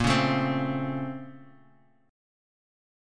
A#mM9 Chord
Listen to A#mM9 strummed